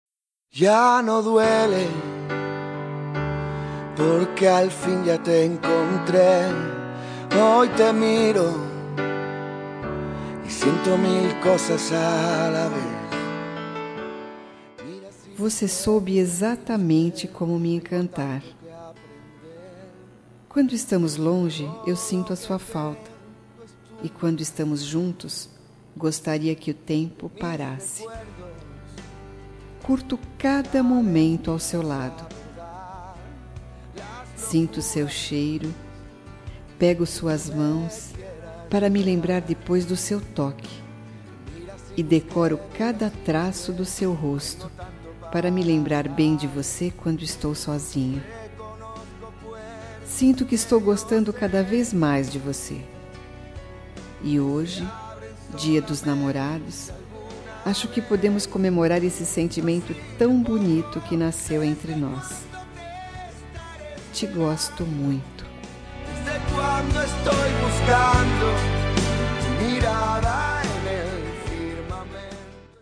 Telemensagem Dia Dos Namorados Ficante
Voz Feminina